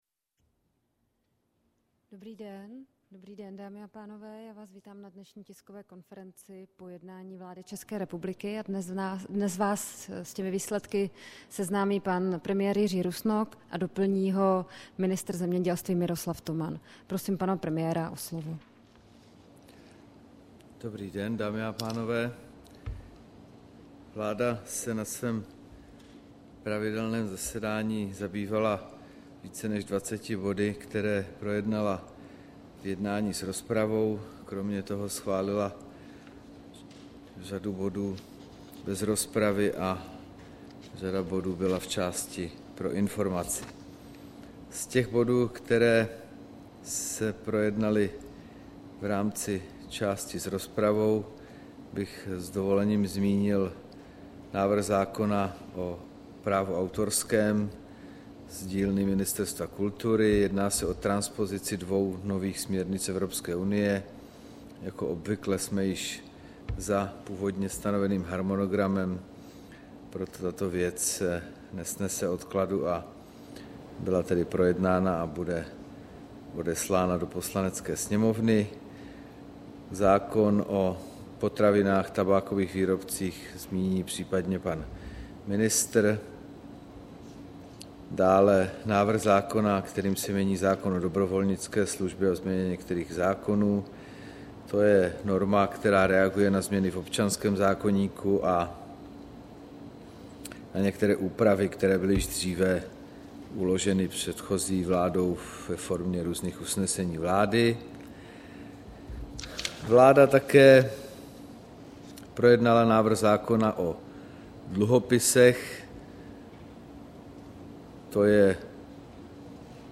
Tisková konference po jednání vlády, 11. prosince 2013